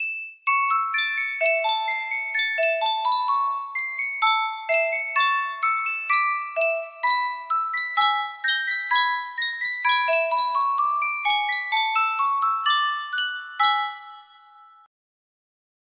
Superstar Music Box - Stars and Stripes Forever 9S music box melody
Full range 60